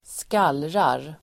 Uttal: [²sk'al:rar]